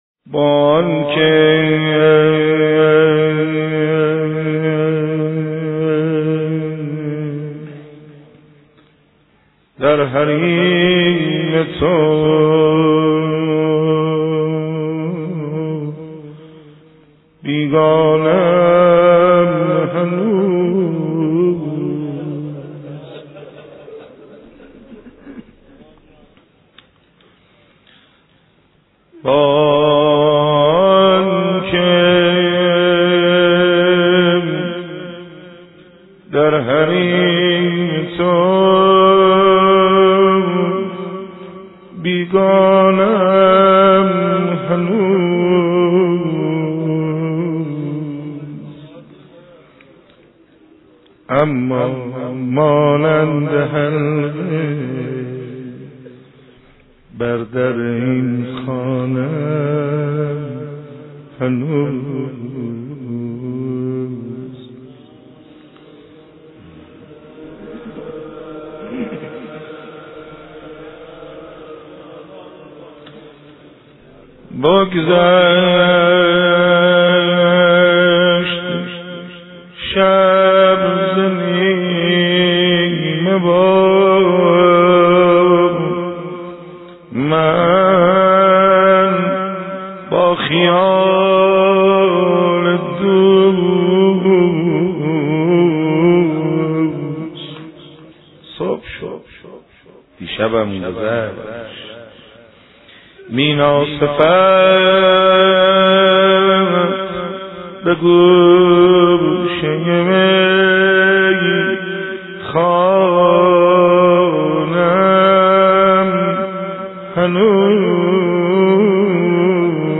با آنکه در حريم تو بيگانه ام هنوز... مانند حلقه بر در اين خانه ام هنوز  (نوحه)